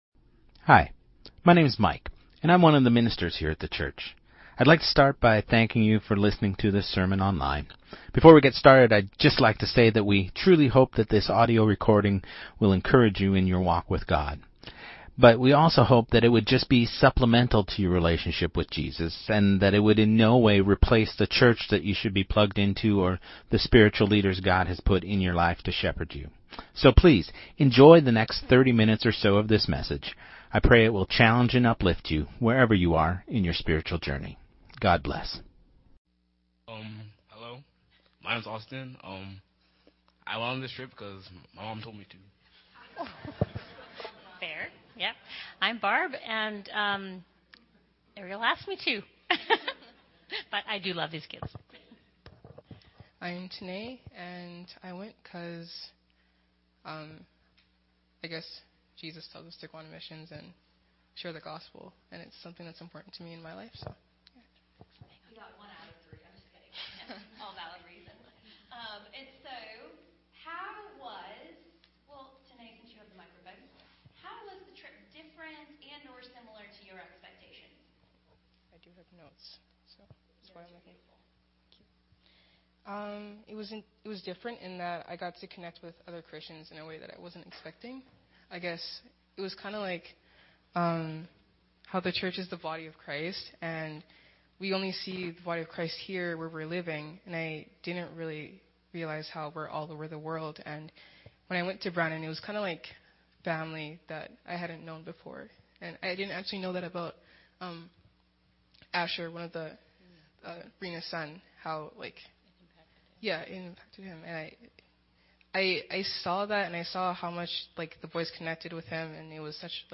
Sermon2025-05-04